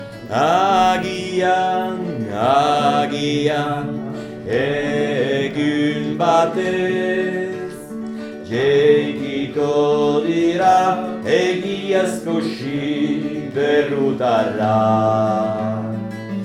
hommes_part2.mp3